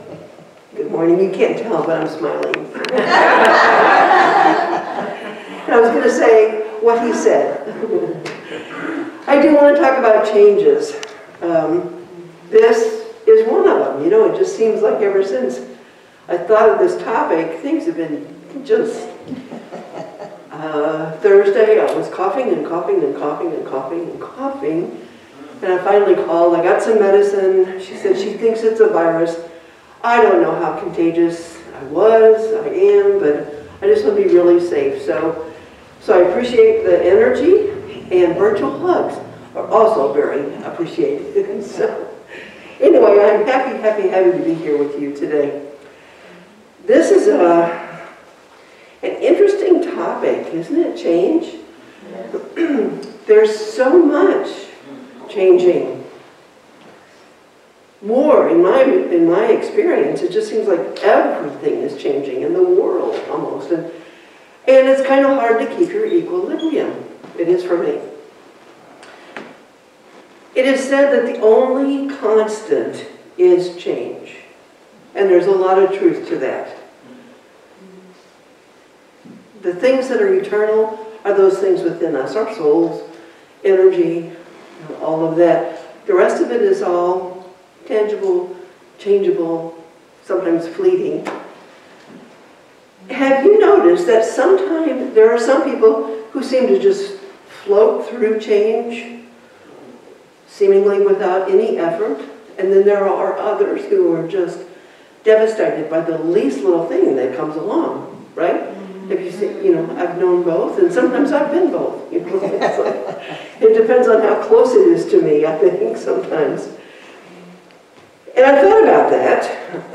Series: Sermons 2025